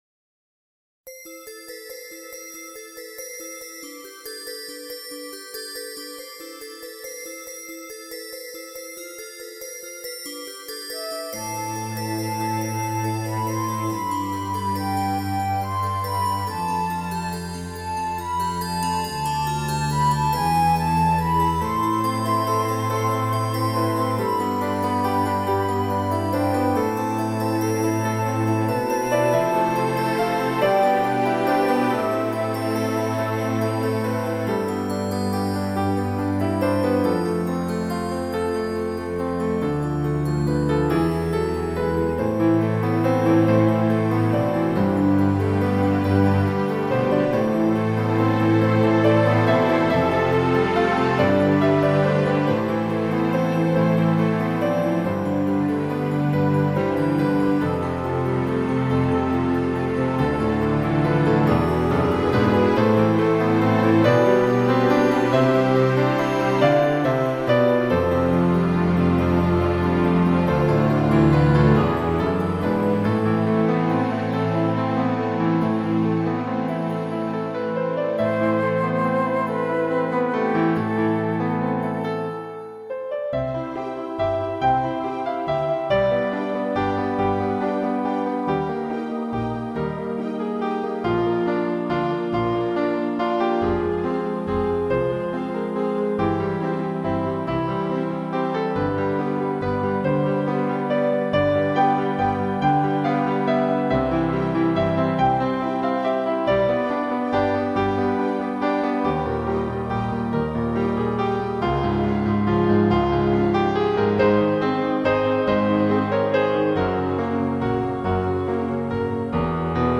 Much of it goes back more than 20 years, so please forgive some of the sound quality.
(Sort of) Recent Digital Compositions (2002-2008)
The use of hardware rack synths, digital pianos from Roland and GeneralMusic, and software synths required a more robust software system.